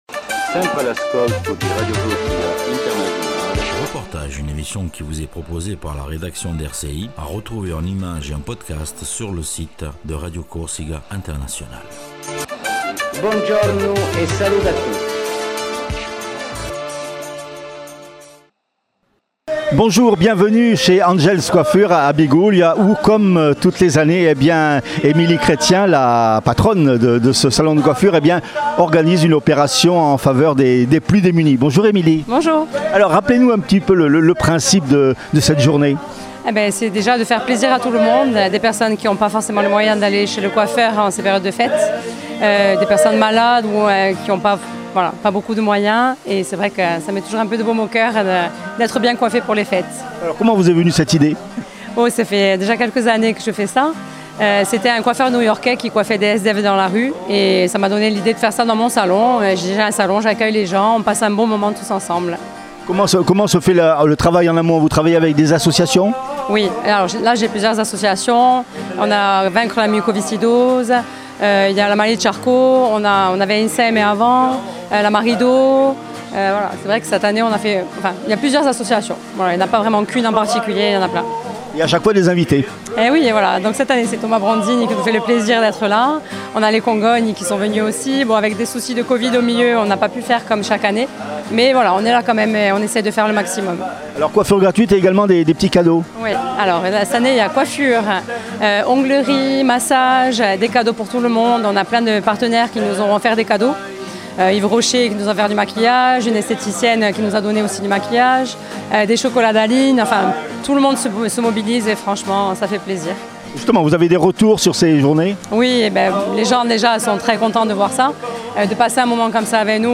Reportage journée solidaire salon Angel's Coiffure Biguglia